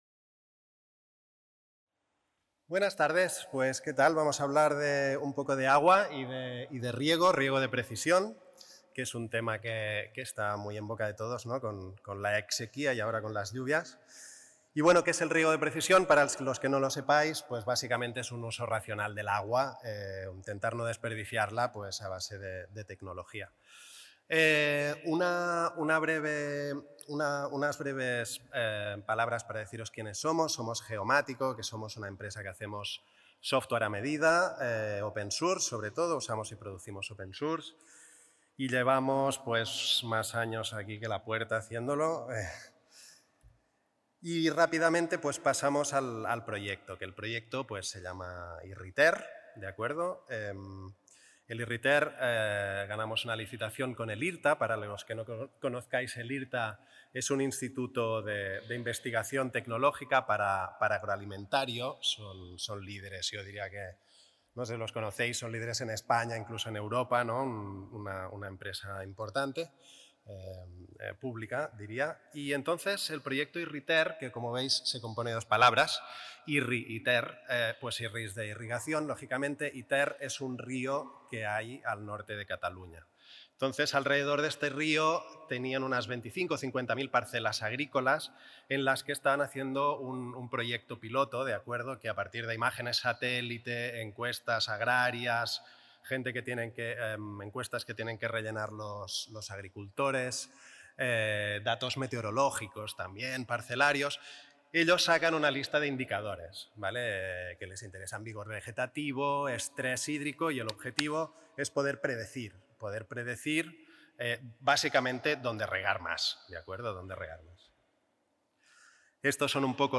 Xerrada on s'explica com s'ha elaborat el programari necessari per visualitzar fàcilment les zones de regadiu del riu Ter i com millorar la gestió per evitar el malbaratament de l'aigua